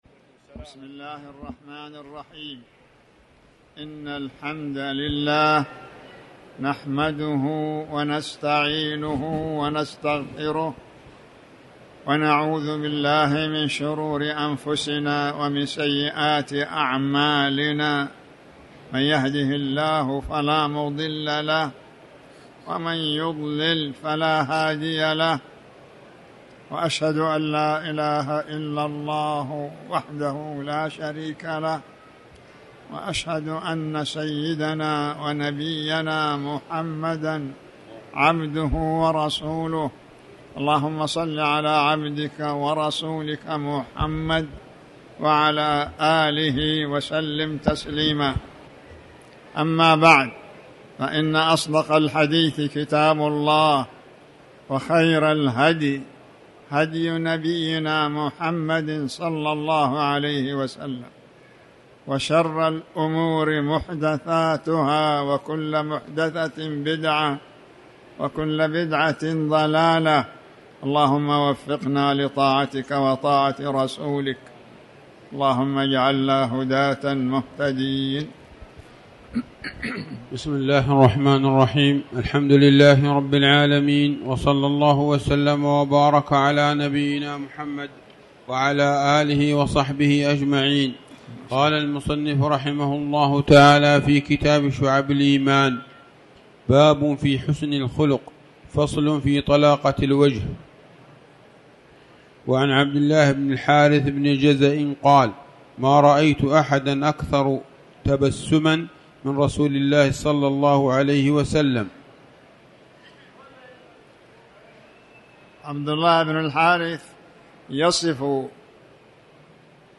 تاريخ النشر ٤ ذو القعدة ١٤٤٠ هـ المكان: المسجد الحرام الشيخ